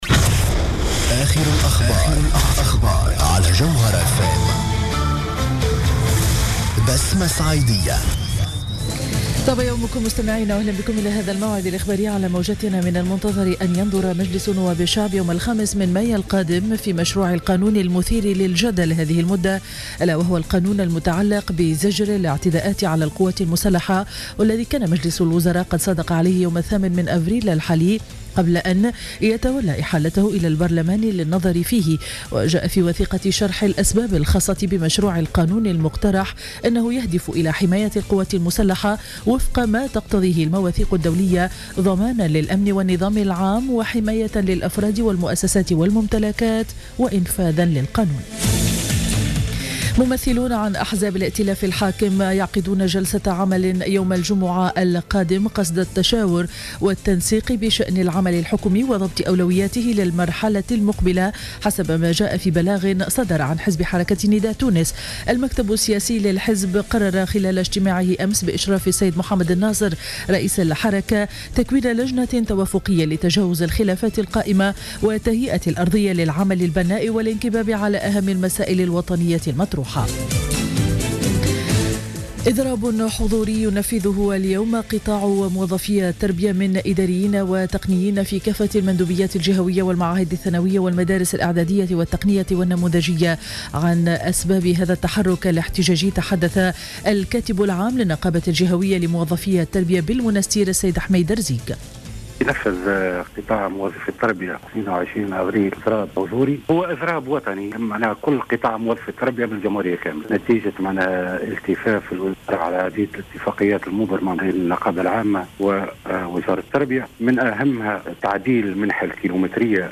نشرة أخبار السابعة صباحا ليوم الإربعاء 22 أفريل 2015